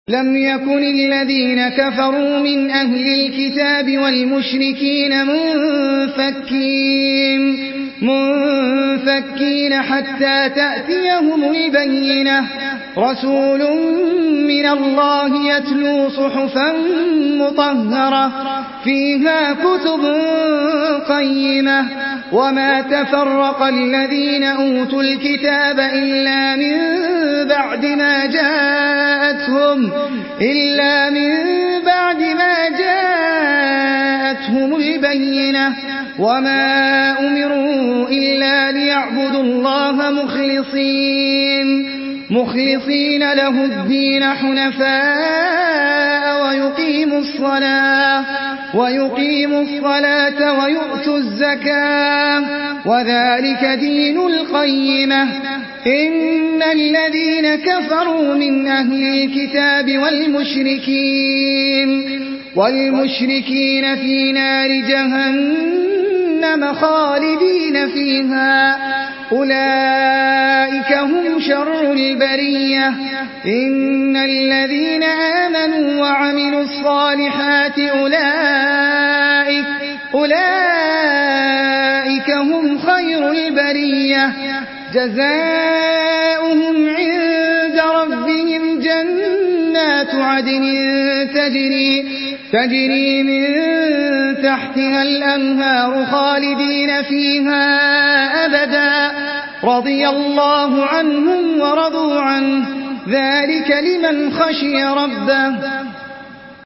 Surah Beyyine MP3 by Ahmed Al Ajmi in Hafs An Asim narration.
Murattal Hafs An Asim